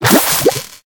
Cri de Toxizap dans Pokémon HOME.